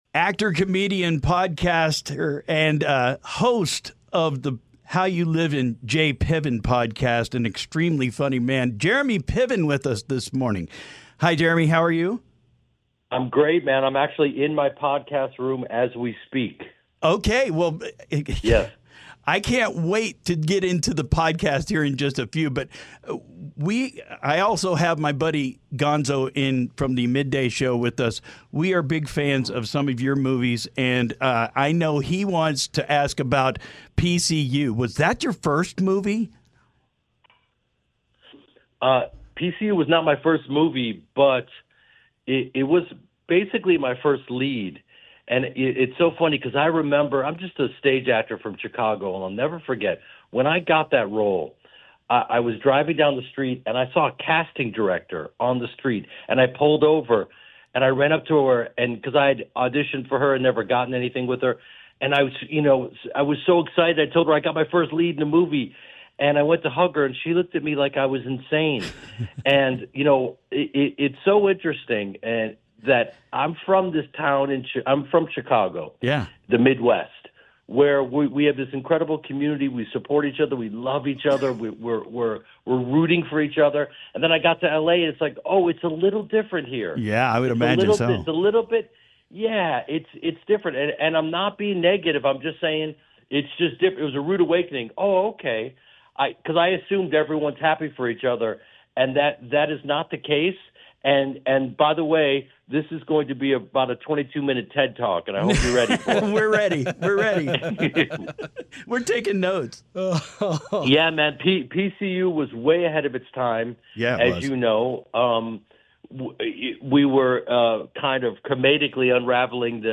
jeremy-piven-interview.mp3